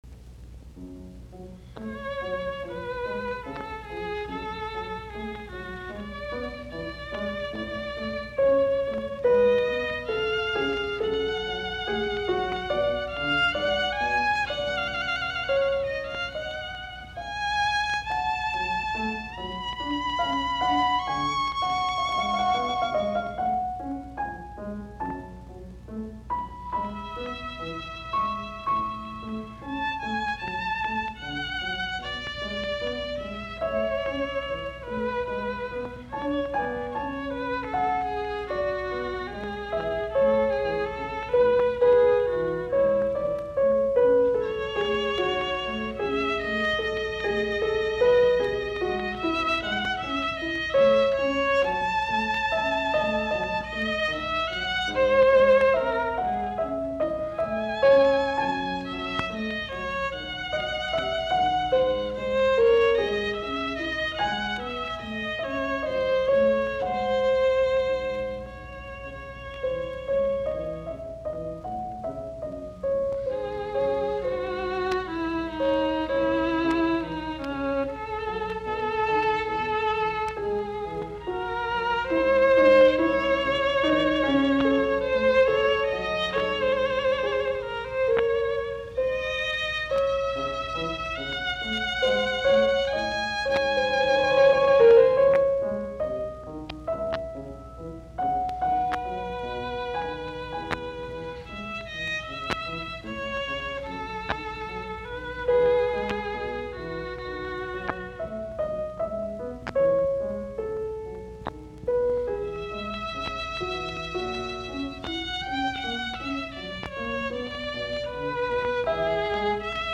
BWV1015, A-duuri; sov. viulu, piano
Soitinnus: Viulu, piano.